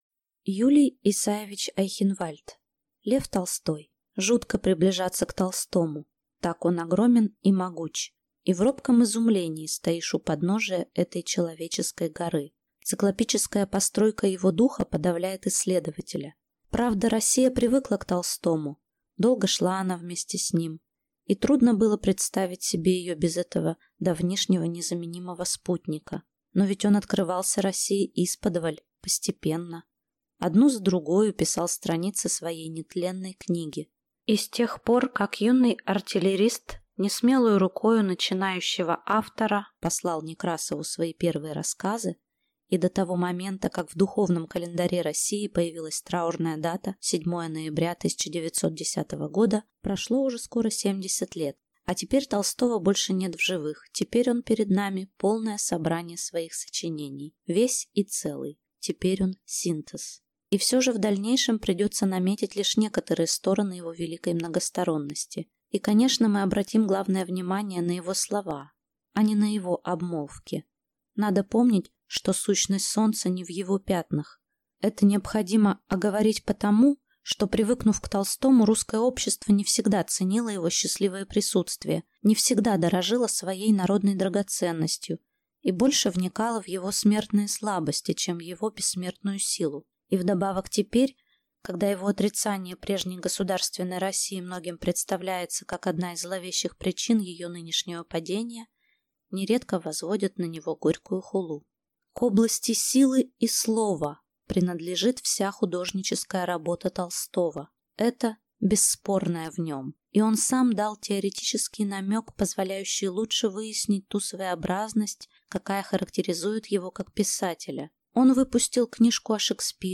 Аудиокнига Лев Толстой | Библиотека аудиокниг